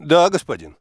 • Качество: 330, Stereo
голосовые